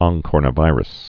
(ŏn-kôrnə-vīrəs, ŏng-)